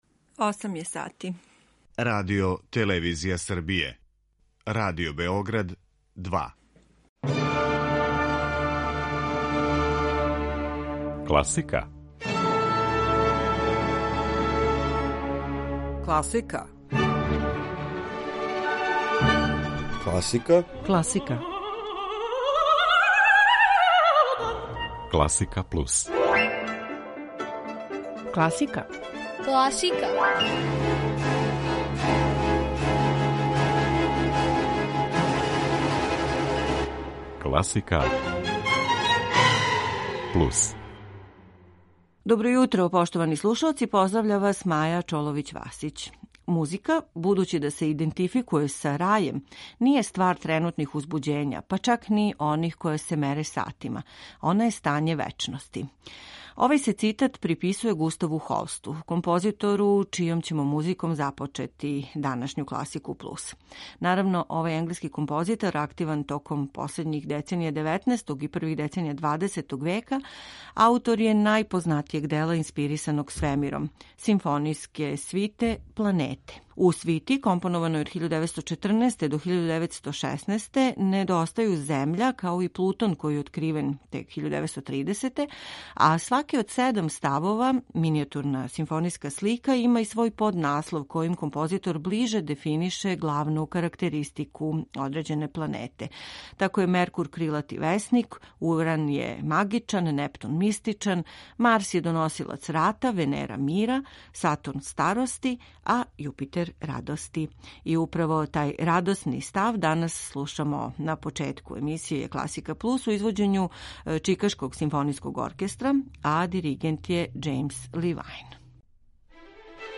У рубирици „На други начин", у интерпретацији Михале Петри, слушамо неке познате нумере Николаја Римског-Корсакова и Никола Паганинија у аранжману за блок-флауту.